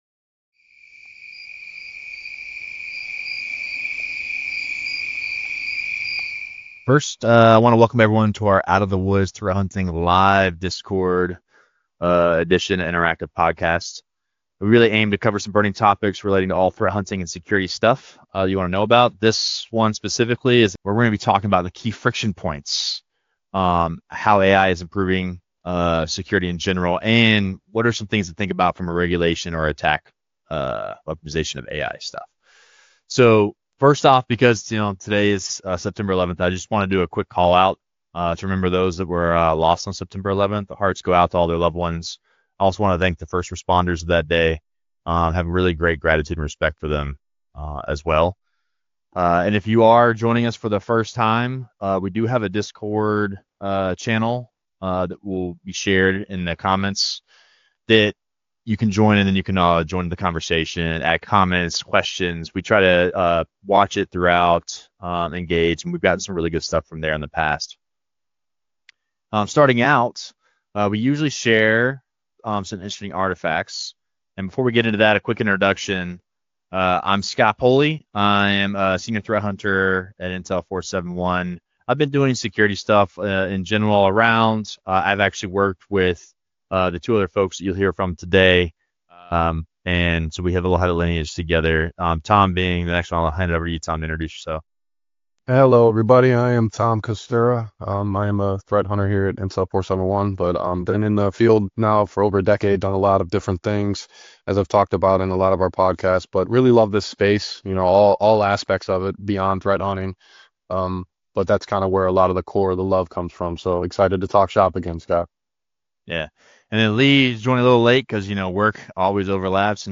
[LIVE] AI for Security Teams: Scaling Impact Without Losing Control